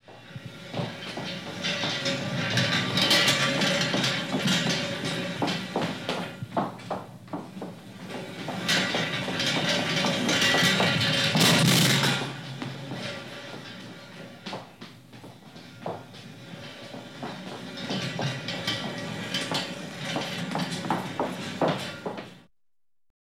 Кто-то гоняет металлический бидон по полу и пинает его